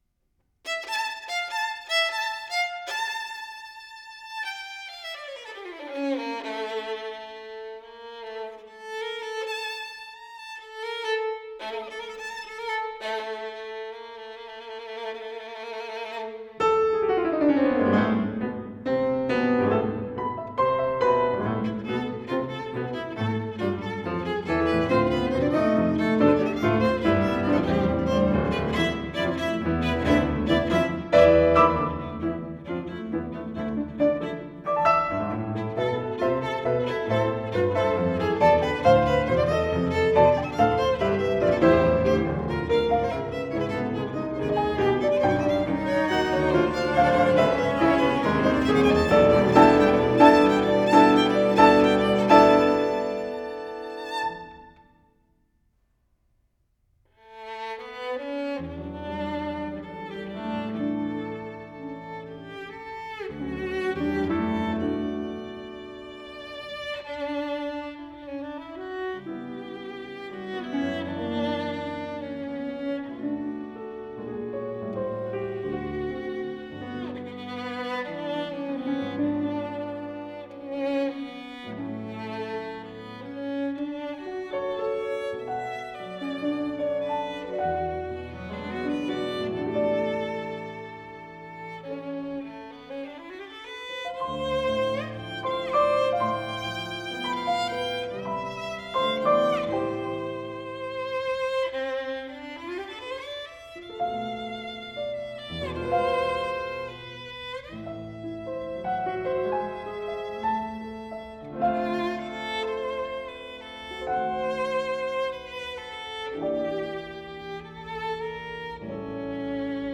忧伤动人的旋律，小提琴声线如泣如诉。